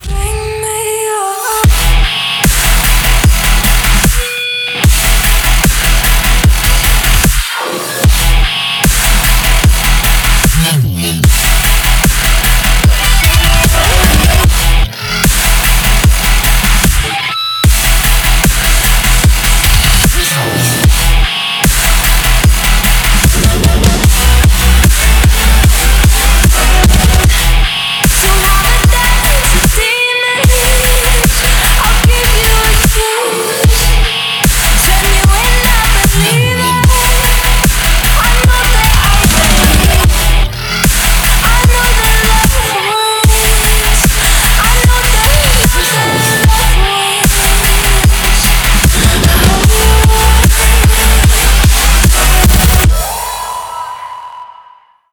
громкие
жесткие
мощные
Electronic
EDM
мощные басы
электрогитара
красивый женский голос
Dubstep